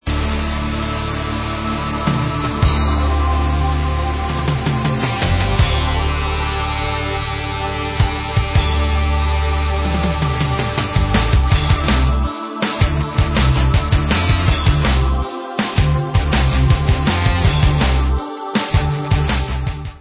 sledovat novinky v oddělení Rock/Progressive